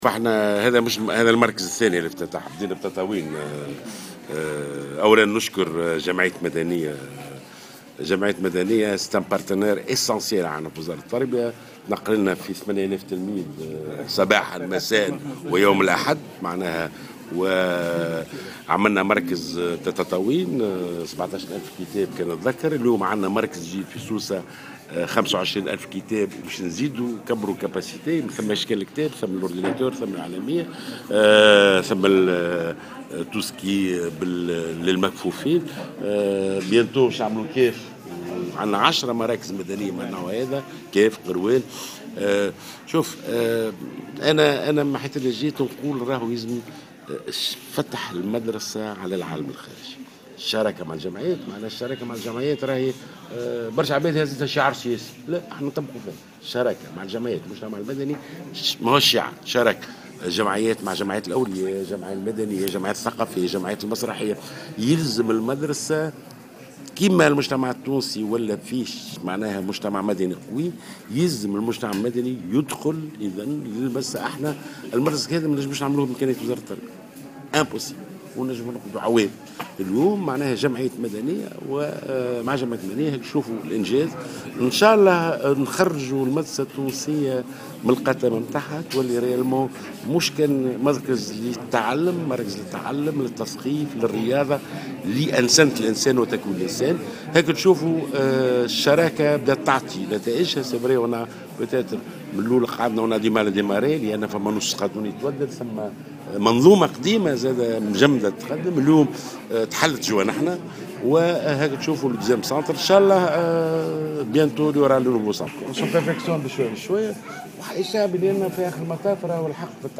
أكد وزير التربية ناجي جلول في تصريح للجوهرة "اف ام" اليوم الثلاثاء على هامش زيارة أداها إلى سوسة لإفتتاح مركز تدشين مركز الكتاب والبحوث بسوسة أن هذه التجربة ستعمم حيث سيتم احداث 10 مراكز مدنية في عدة ولايات تونسية أخرى.